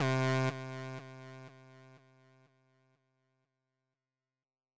ORG Trumpet C2.wav